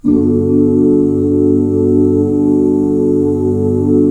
GMAJ7 OOO -R.wav